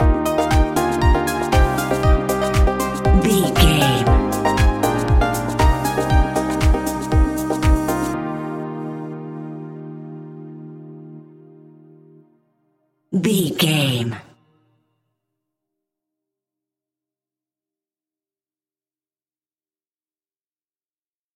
Ionian/Major
E♭
uplifting
energetic
cheerful/happy
bouncy
piano
electric piano
drum machine
synthesiser
house
electro house
synth leads
synth bass